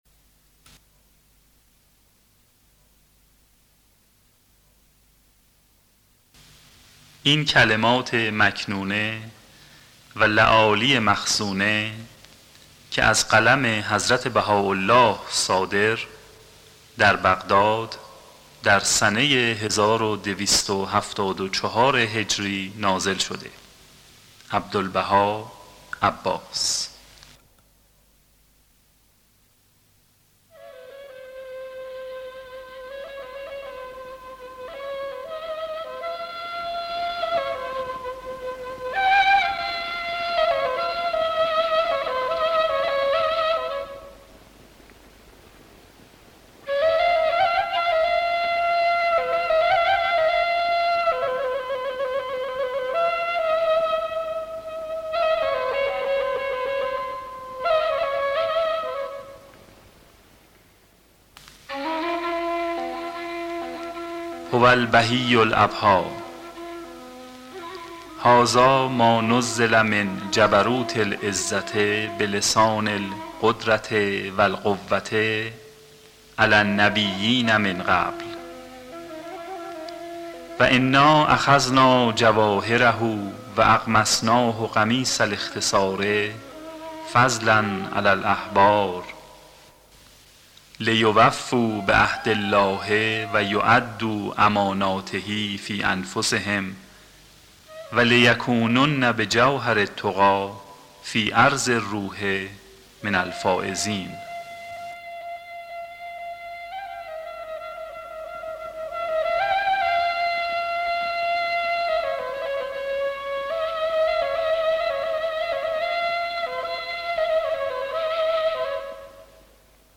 دانلود kalamate maknooneh arabi.mp3 سایر دسته بندیها کتابهای صوتی 27787 بازدید افزودن دیدگاه جدید نام شما موضوع دیدگاه * اطلاعات بیشتر درباره قالب‌بندی متن چه کدی در تصویر می‌بینید؟